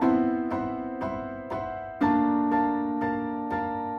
Index of /musicradar/gangster-sting-samples/120bpm Loops
GS_Piano_120-C1.wav